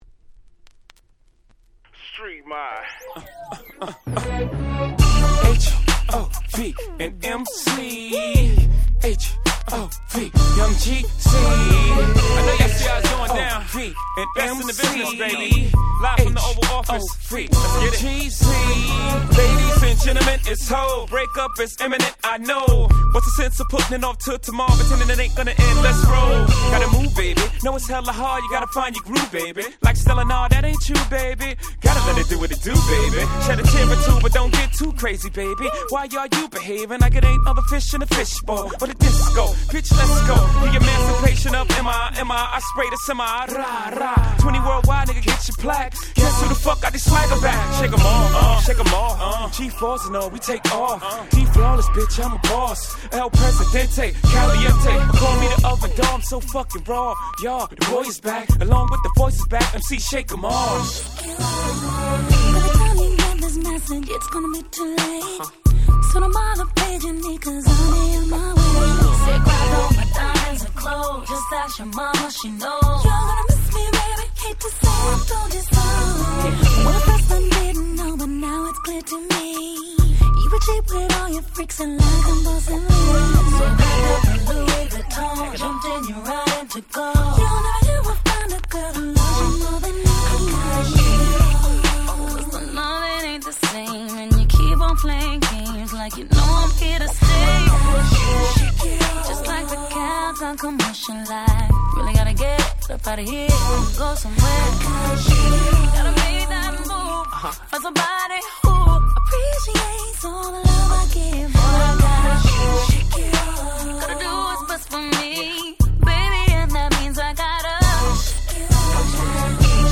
Slow Jam スロウジャム